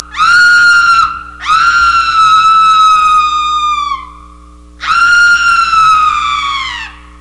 Woman Scream Sound Effect
Download a high-quality woman scream sound effect.
woman-scream.mp3